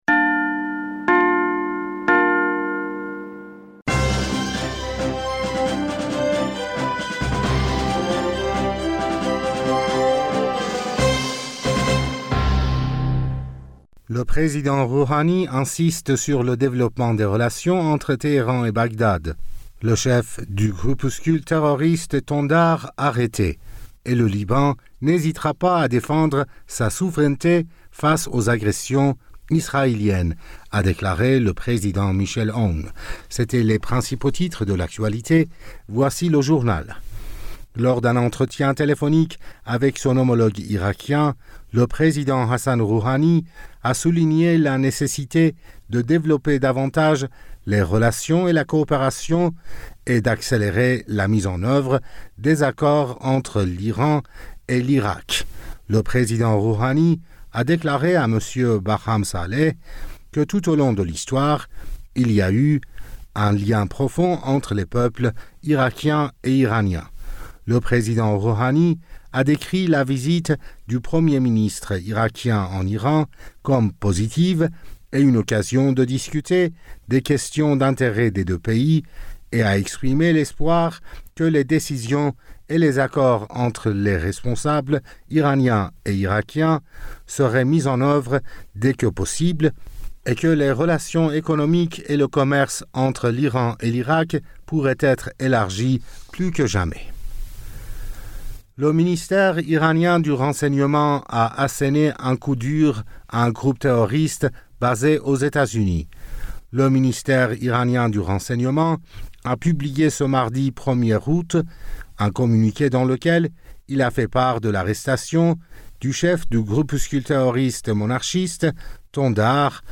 Bulletin d'information du 01 Aout 2020